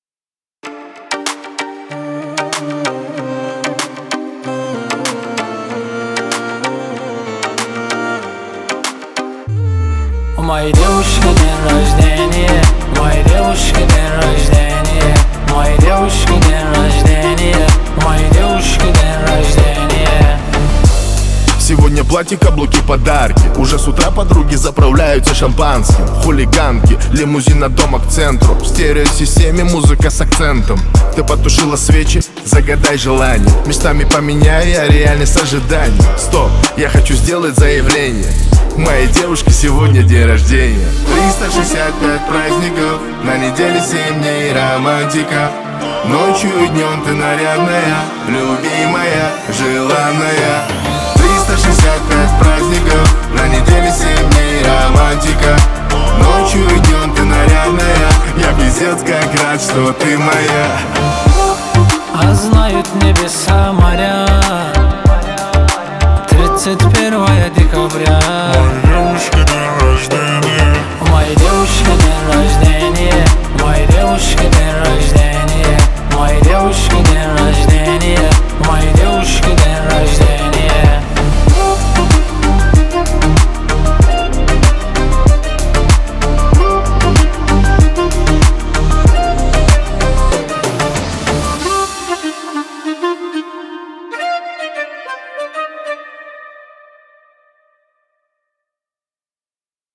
это яркая и запоминающаяся композиция в жанре поп